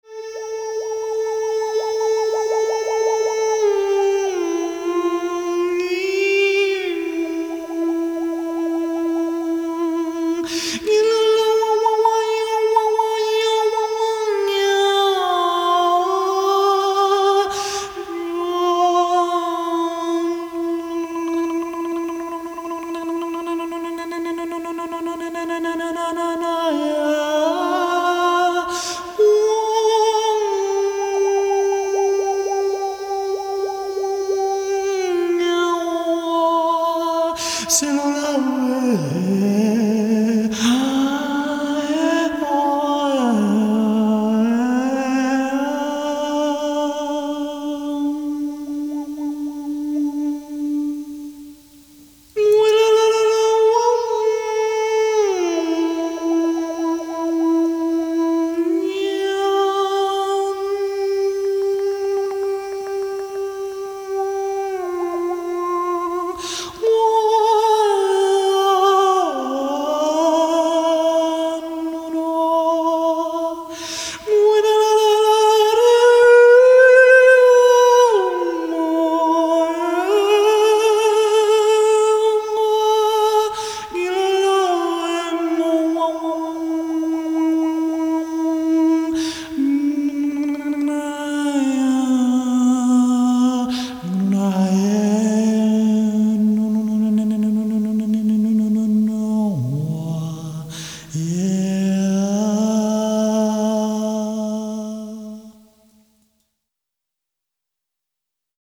Genre: World Music
Recording: Windwood Studios